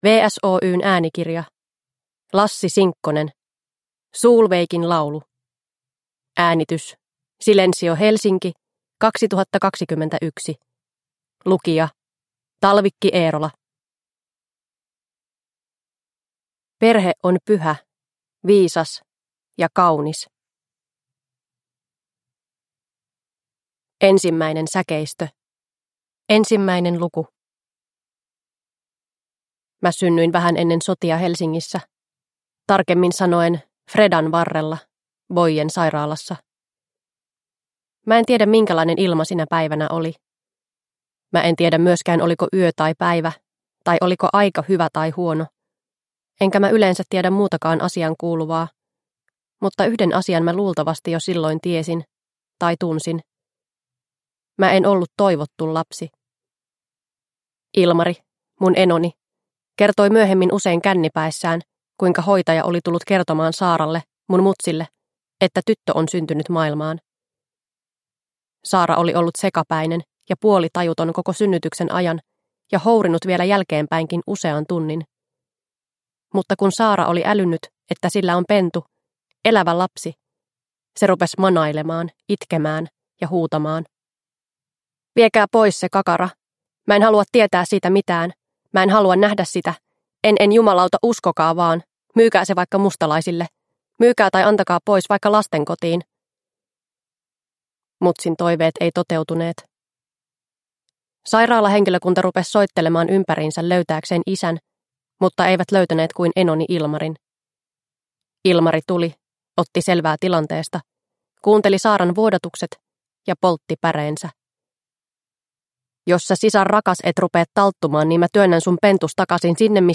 Solveigin laulu – Ljudbok – Laddas ner